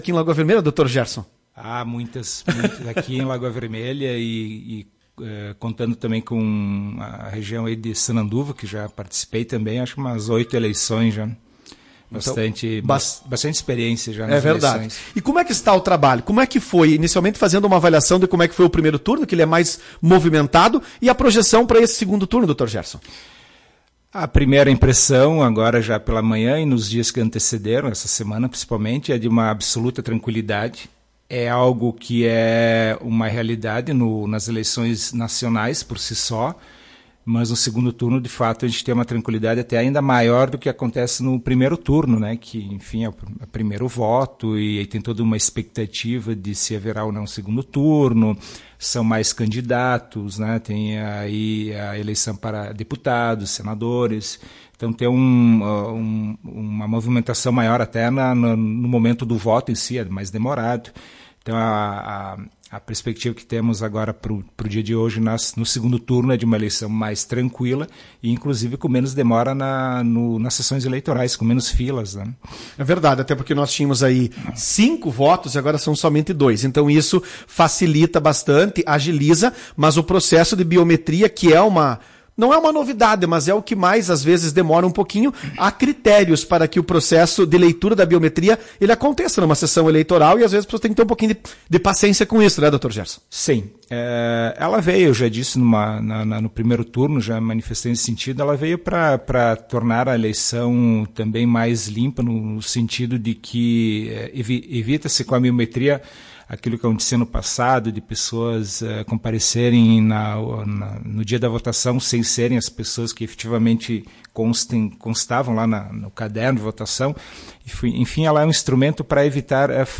Em entrevista neste domingo, 30 de outubro, Lira também avaliou as diferenças presentes nesta eleição, e como as fake news e a internet influenciam, ou tentam influenciar, o pleito.